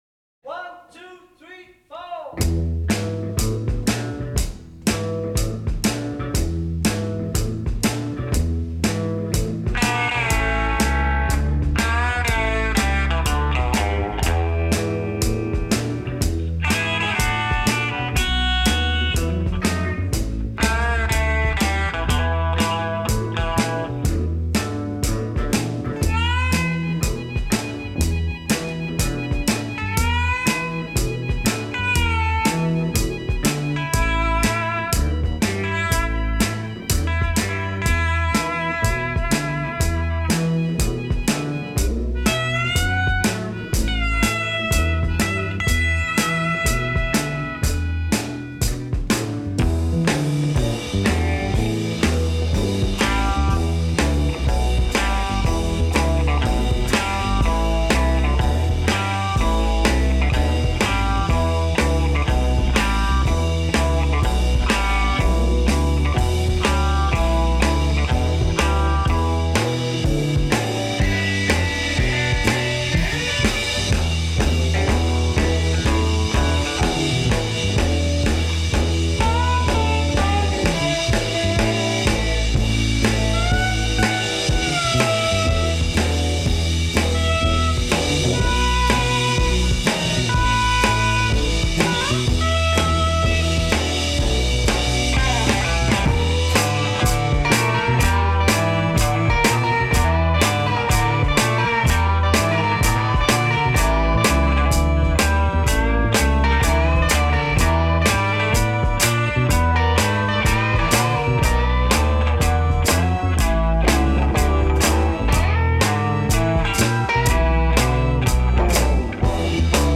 инструментальная композиция
Запись состоялась 4 ноября 1965 года в студии «Эбби Роуд».
(инструментал)